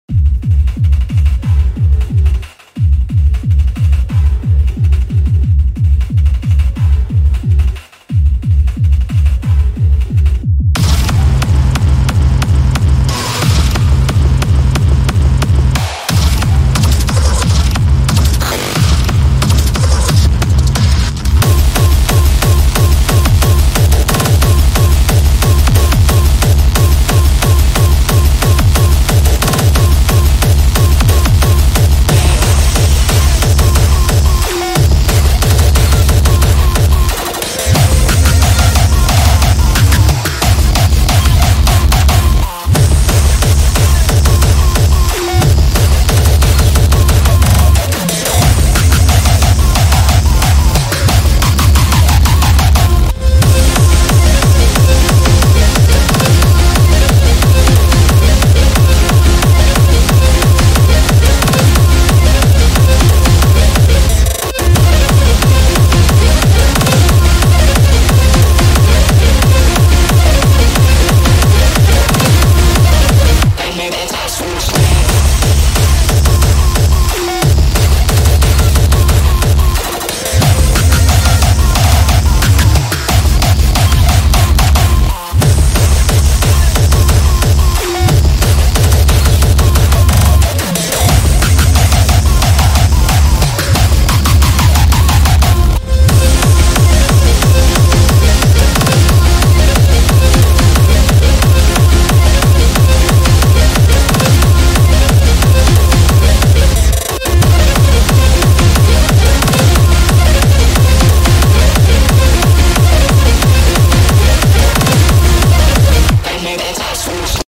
Chase Theme OST